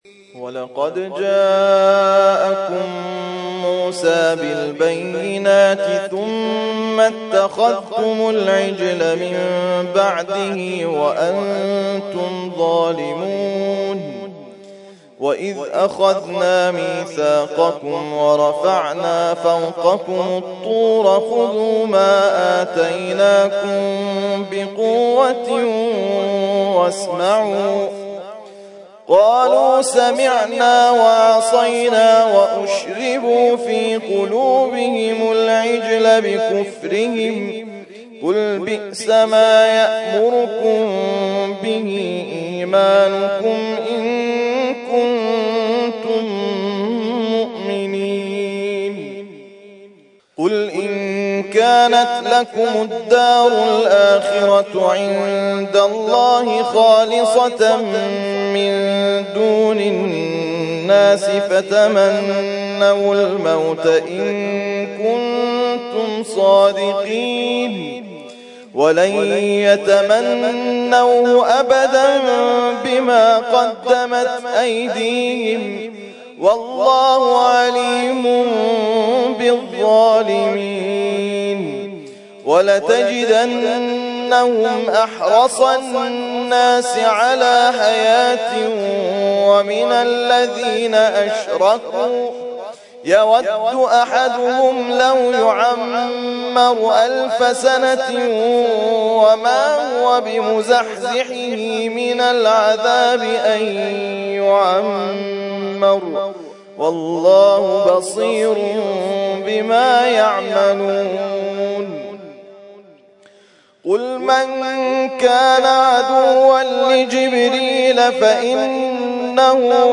ترتیل خوانی جزء ۱ قرآن کریم در سال ۱۳۹۳